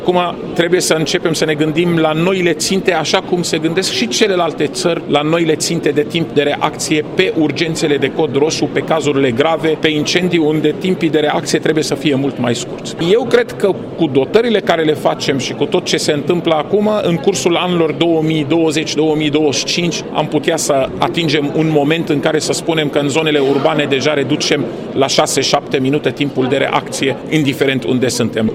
El a participat la primul bilanţ anual al unei structuri IGSU, care a avut loc ieri la Reşiţa.